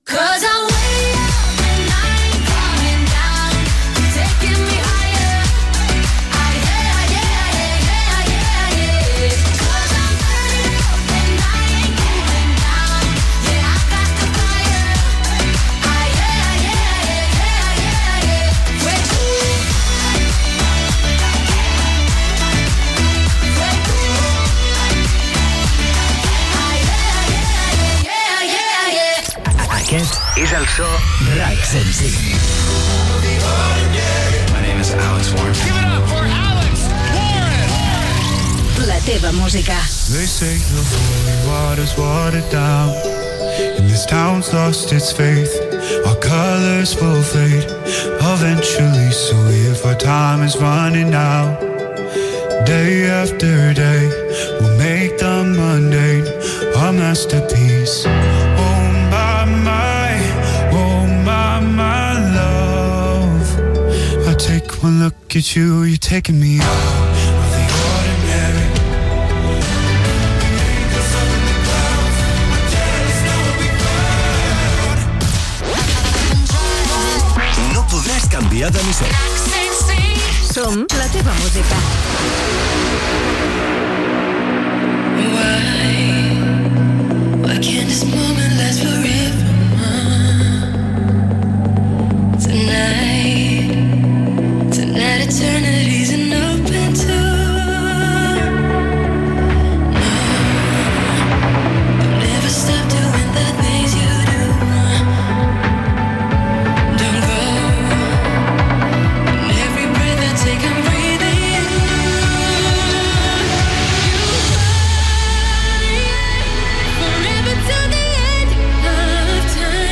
Tema musical, indicatiu de la ràdio, tema musical, indicatiu, tema, anunci del retorn del concert 105 Confidencial i de com poder participar-hi, indicatiu, tema musical
Musical
FM